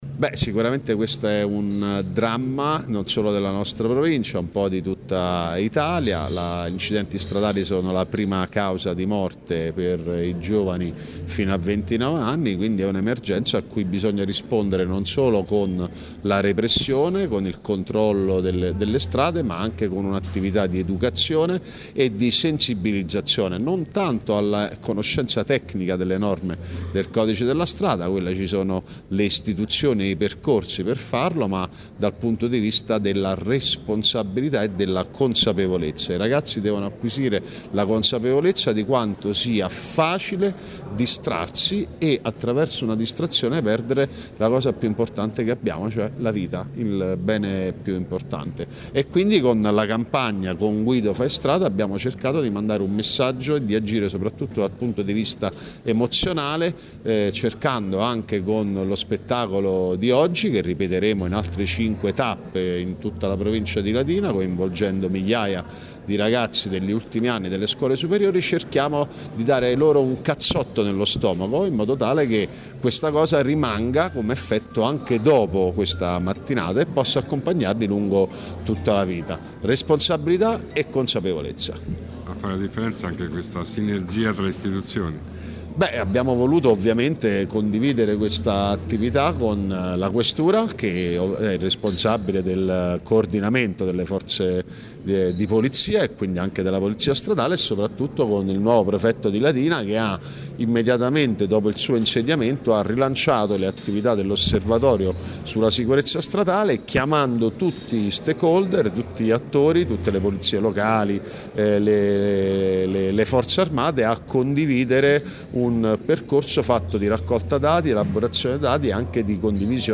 LATINA – E’ in corso al Teatro D’Annunzio di Latina  il primo incontro dedicato alla sicurezza stradale voluto dalla Provincia di Latina, in collaborazione con la Prefettura e la Questura, per sensibilizzare i giovani sul tema e portare avanti le attività dell’Osservatorio sulla sicurezza stradale.